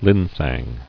[lin·sang]